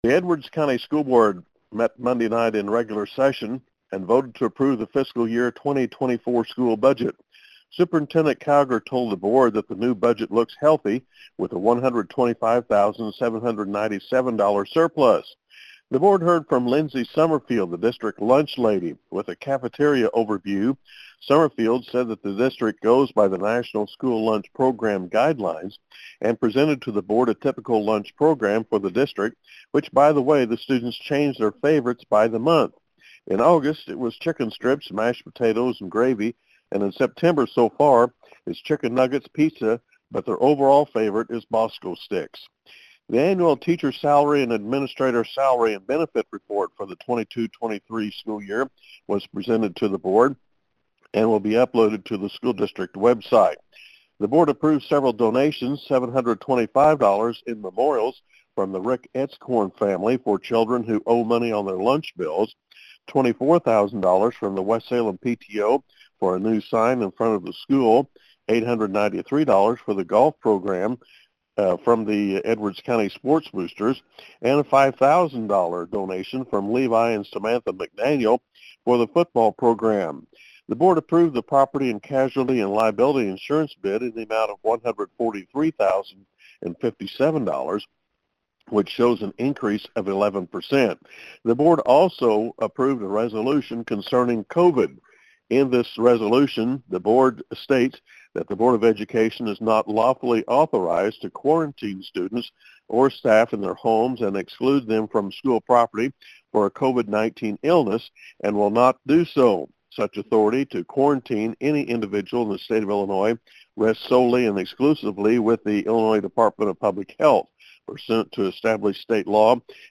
filed this report